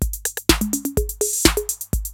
TR-808 LOOP2 3.wav